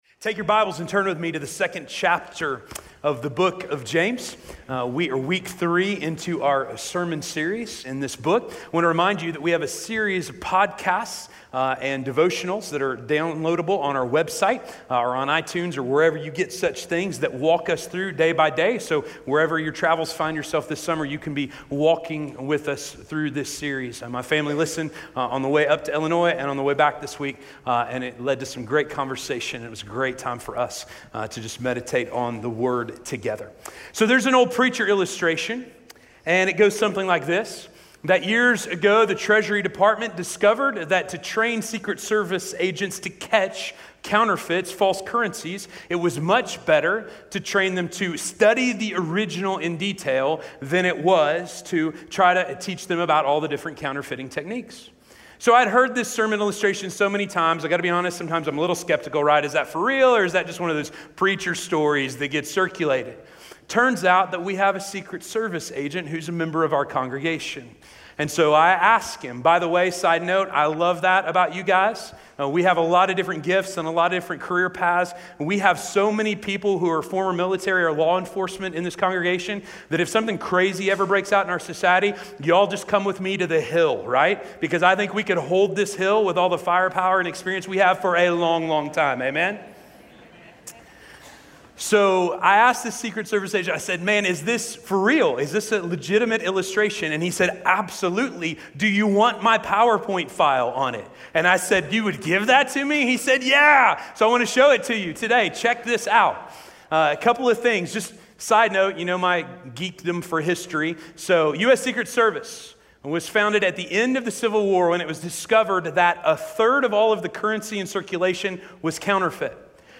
Faith Displayed - Sermon - Station Hill